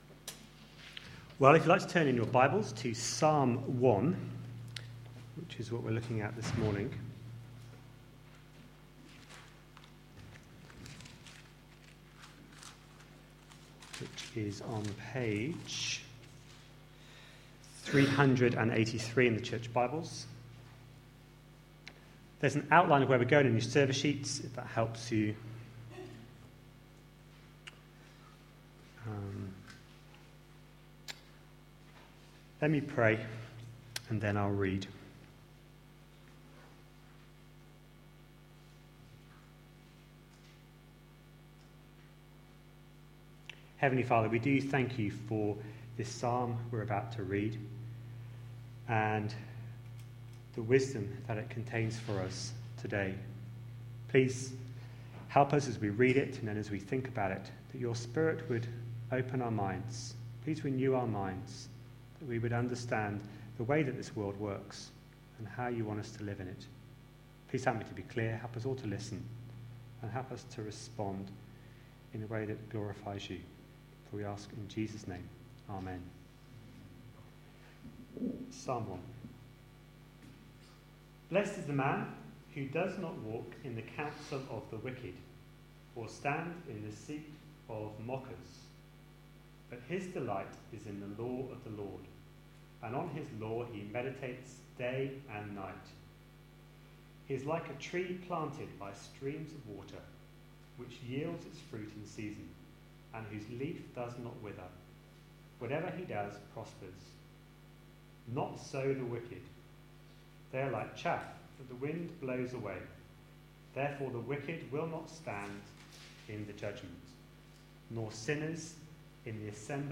A sermon preached on 1st December, 2013.